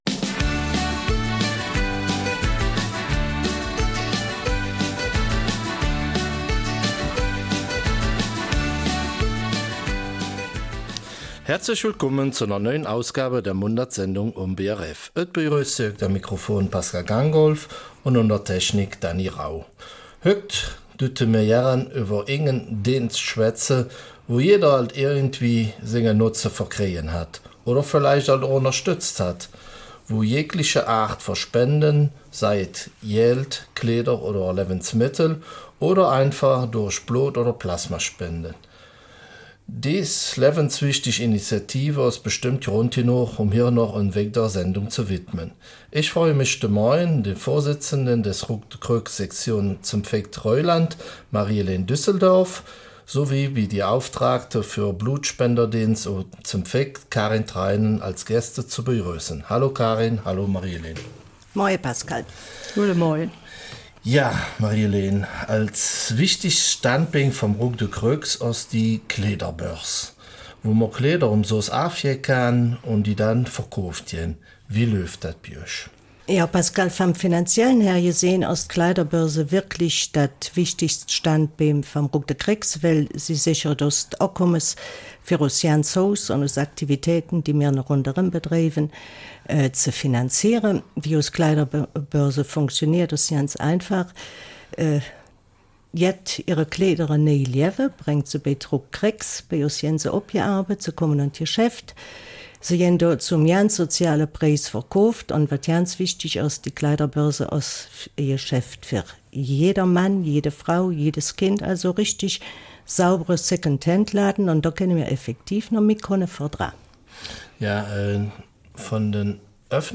Eifeler Mundart: Die Rot-Kreuz-Sektion St. Vith-Reuland